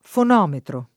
[ f on 0 metro ]